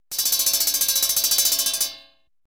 Comedy Bell Sound Effect Free Download
Comedy Bell